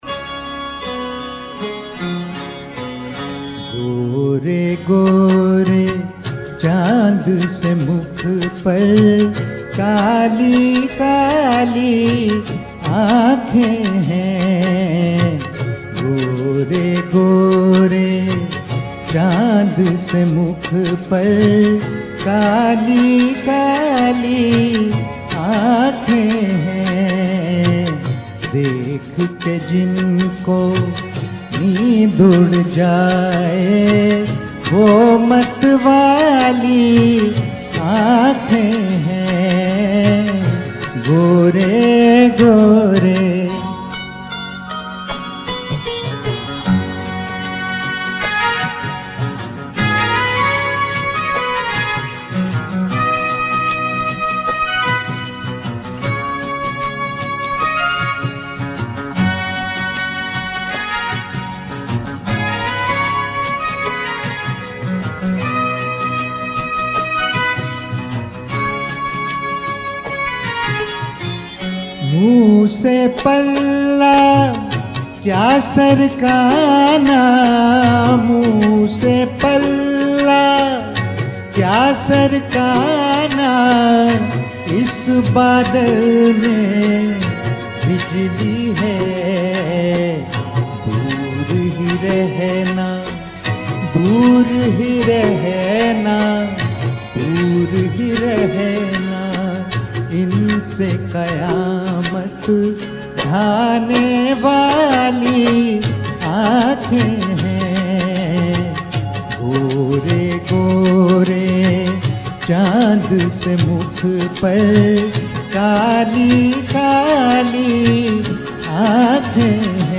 Karaoke